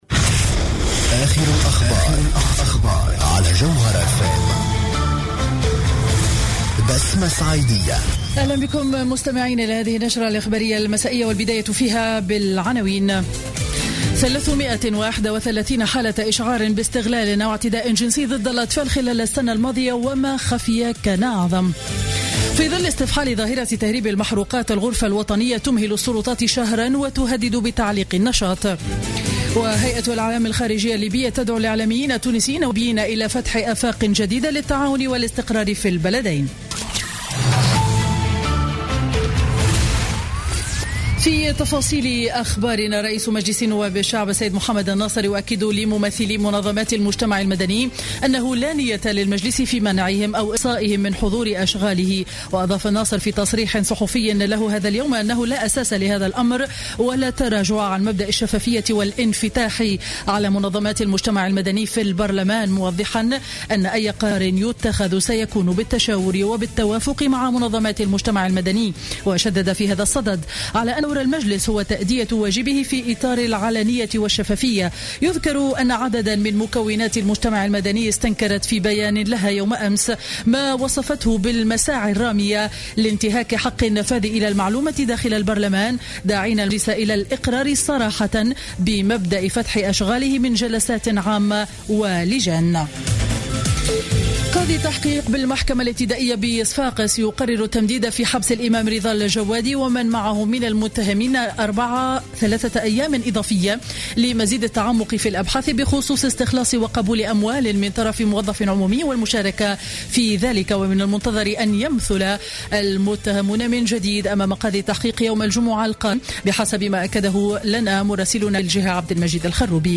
نشرة أخبار السابعة مساء ليوم الثلاثاء 27 أكتوبر 2015